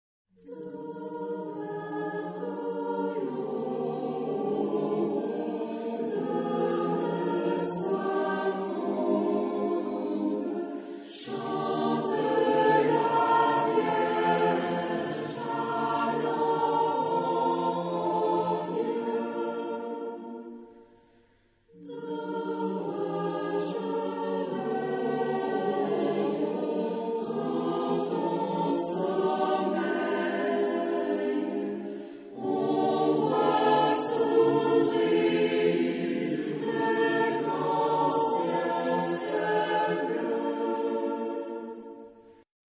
Género/Estilo/Forma: Folklore ; Canción de Navidad ; Profano ; Sagrado ; Tradicional
Tipo de formación coral: SATB  (4 voces Coro mixto )
Tonalidad : fa mayor